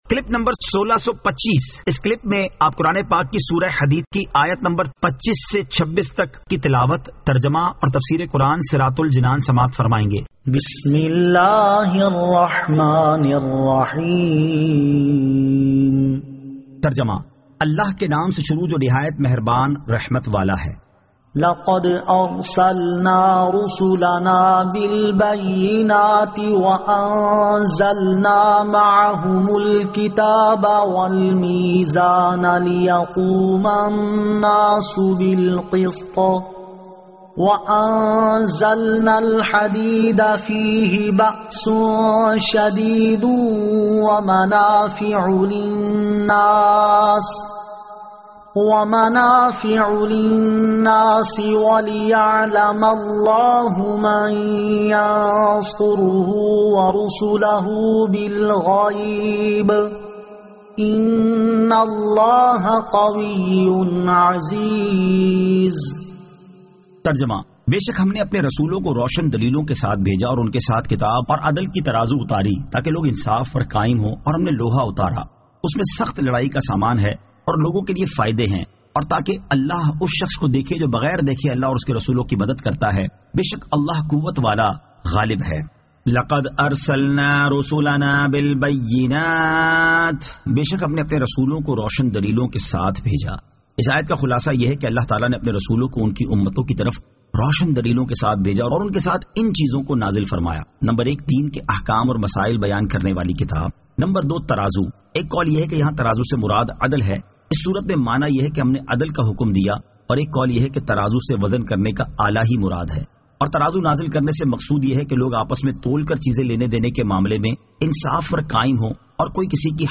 Surah Al-Hadid 25 To 26 Tilawat , Tarjama , Tafseer